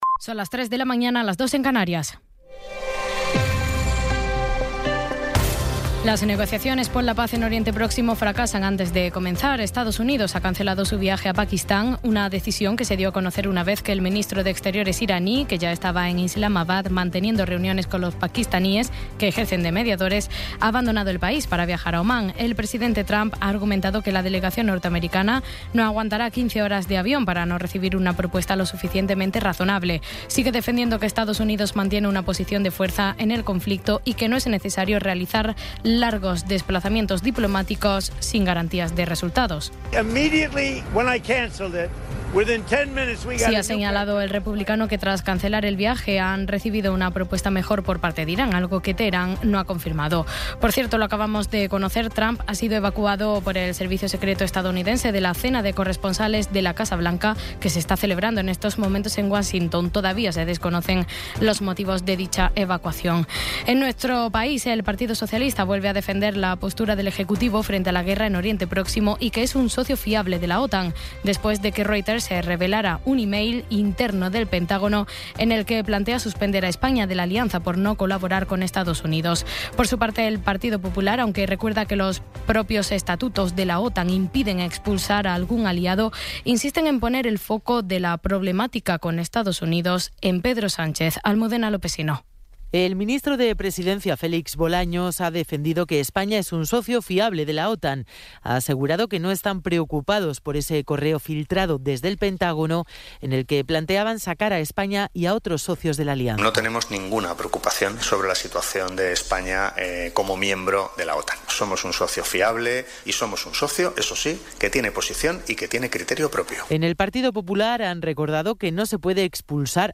Resumen informativo con las noticias más destacadas del 26 de abril de 2026 a las tres de la mañana.